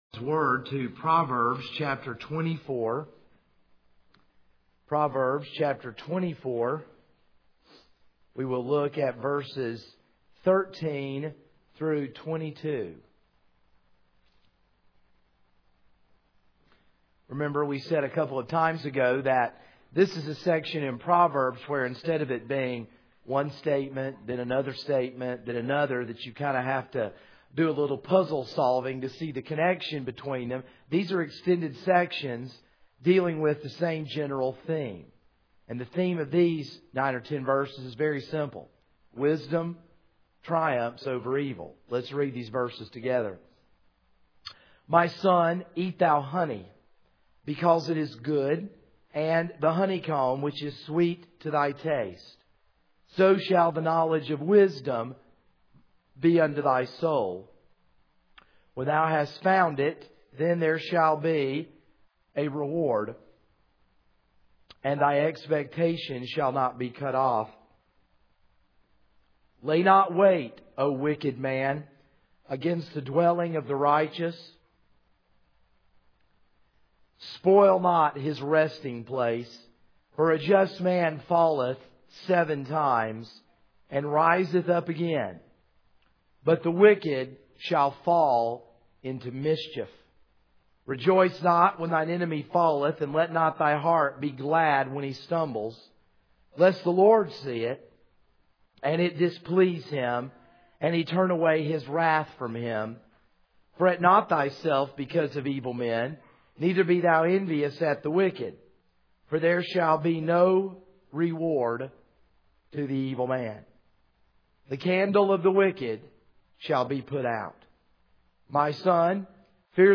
This is a sermon on Proverbs 24:13-22.